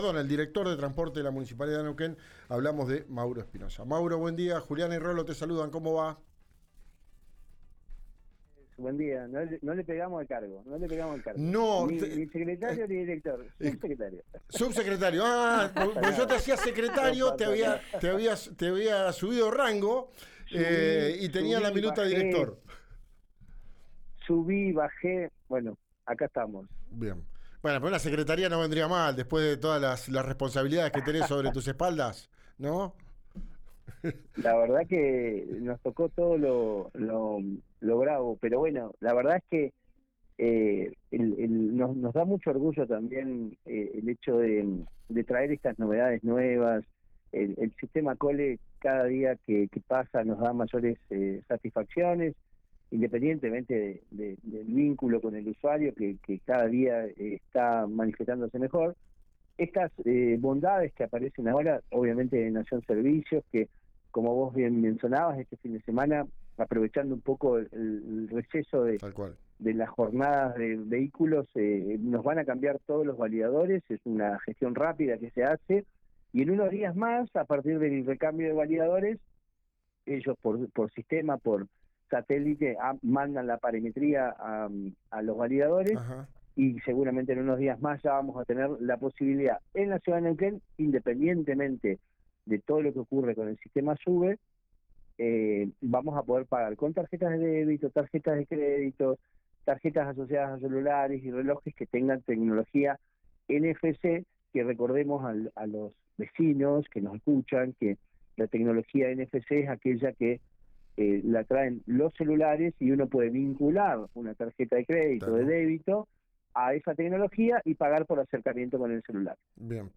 Escuchá la nota al subsecretario de transporte de la municipalidad de Neuquén, Mauro Espinosa acá: